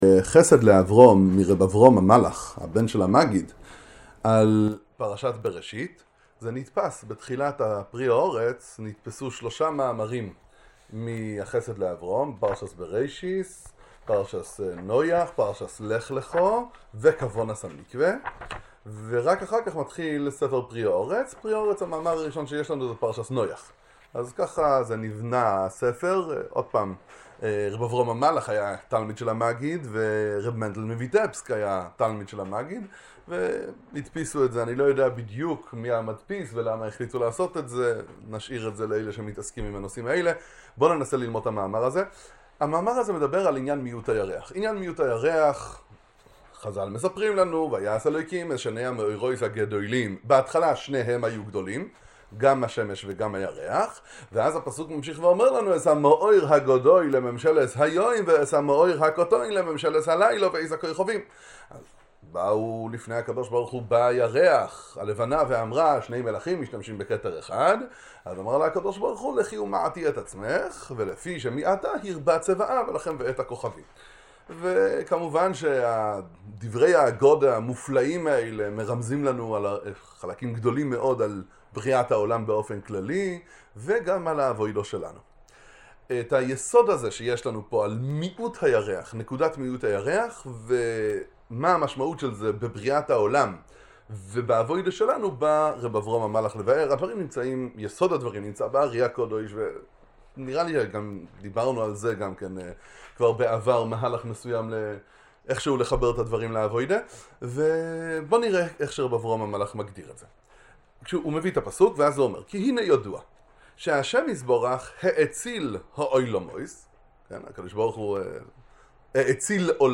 שיעור בספר חסד לאברהם